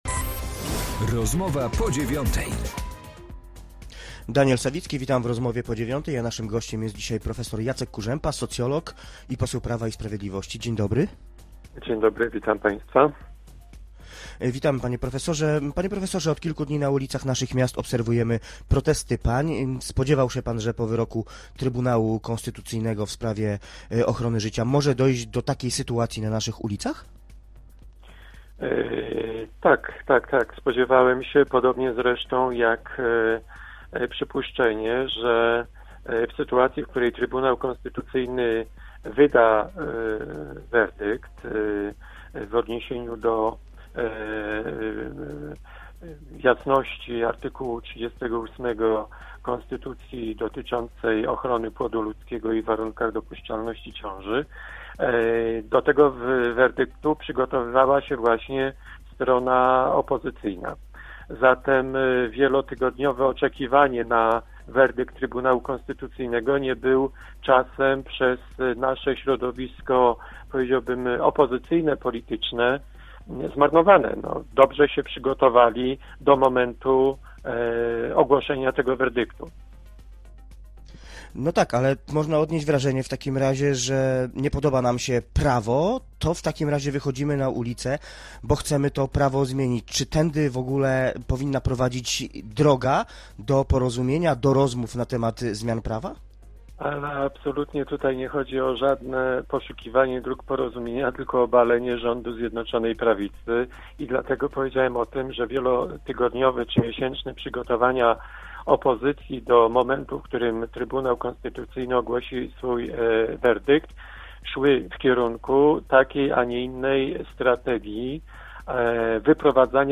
Z posłem Prawa i Sprawiedliwości rozmawia